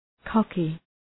{‘kɒkı}